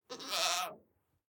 sounds / mob / goat / idle8.ogg